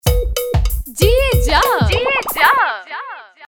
Stingers, Bumpers & Station Jingles